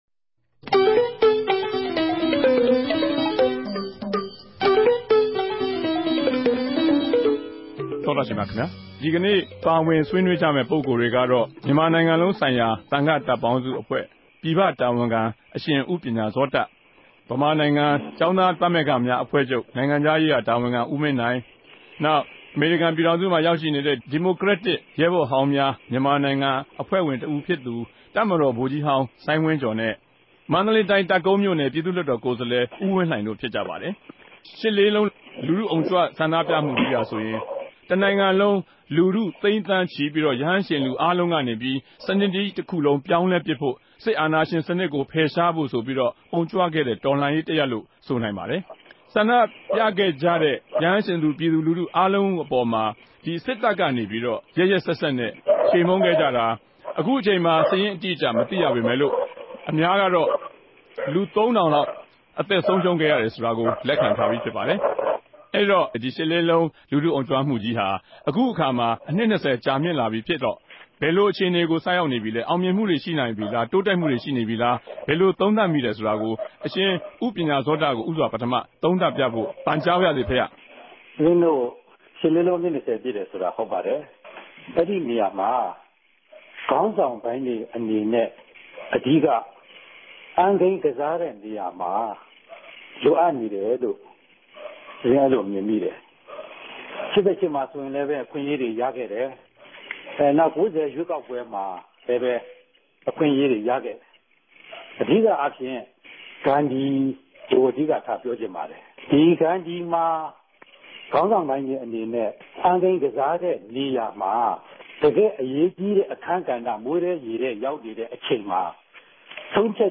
တနဂဿေိံြ စကားဝိုင်း။